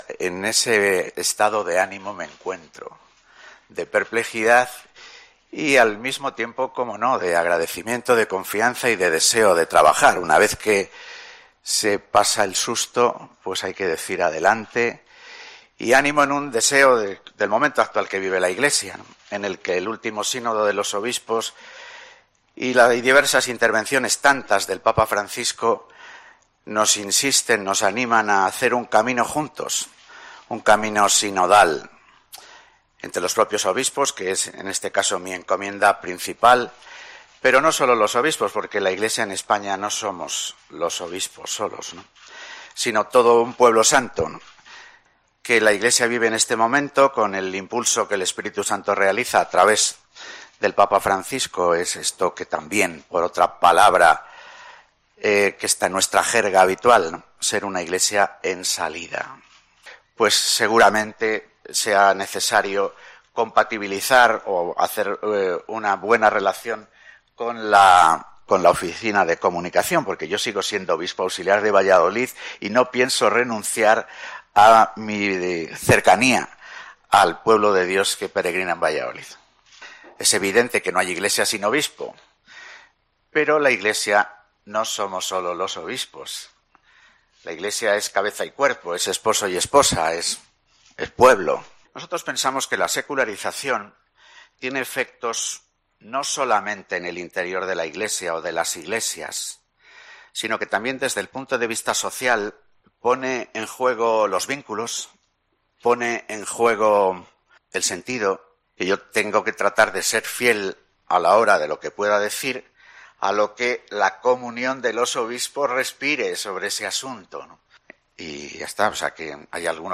Mons. Luis Argüello, el nuevo secretario general de la CEE ha hecho sus primeras declaraciones ante los medios de comunicación
Tras su nombramiento, el nuevo secretario general ha hecho sus primeras declaraciones en la Sede Central de la CEE.